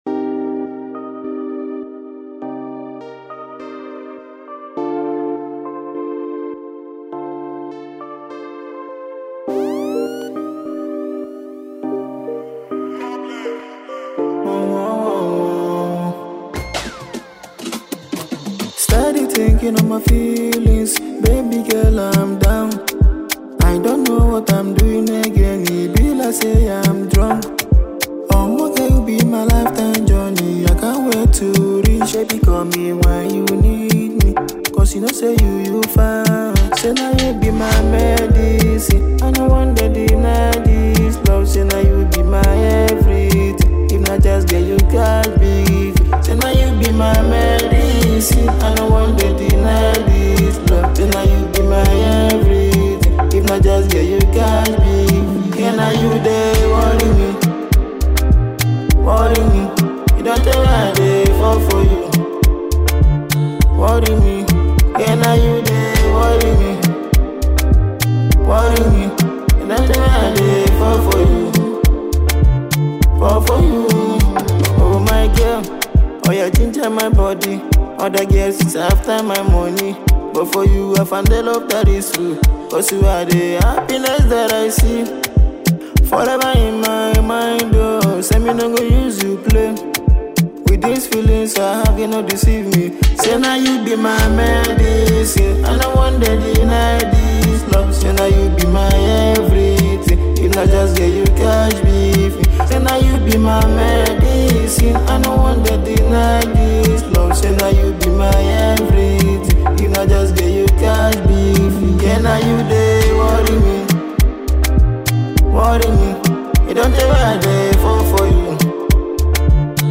captivating and emotive song
With its heartfelt lyrics and soulful melody